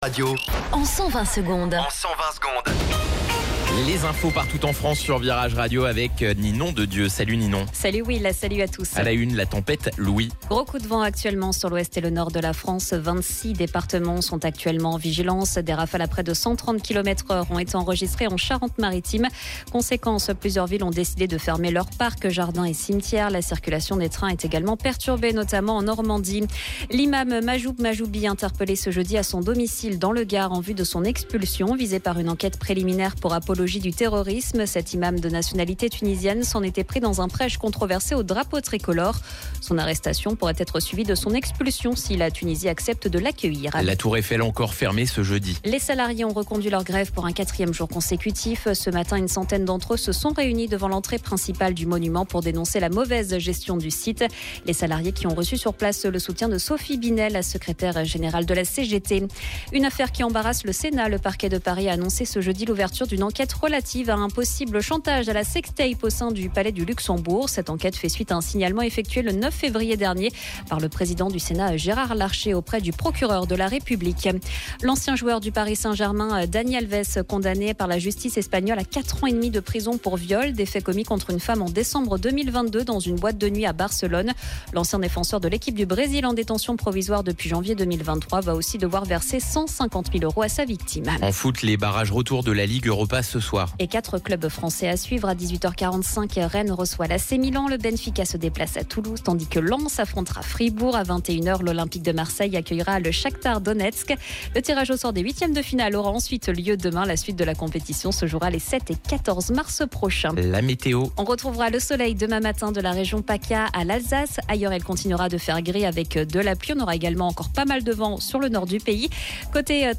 Flash Info National